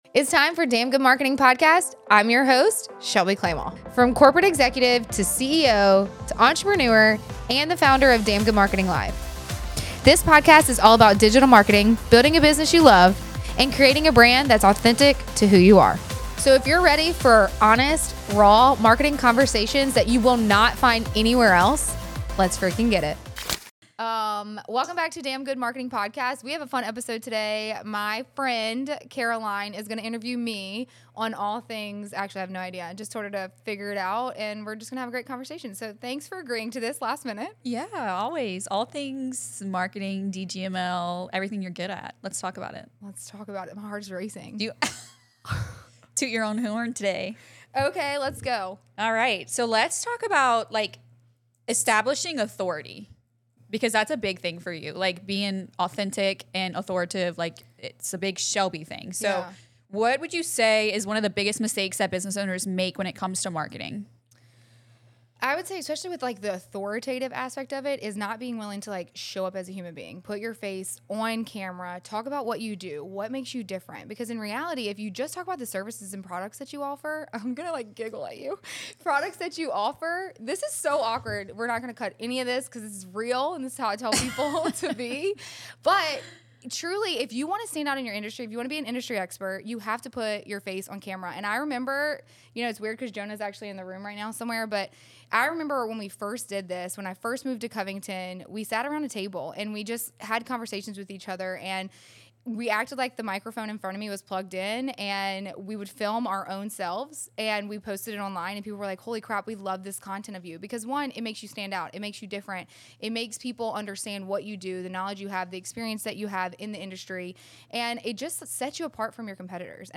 We will discuss the hardships, truths, and what it takes for success. Join me weekly, as I chat with you as your best friend and interview experts.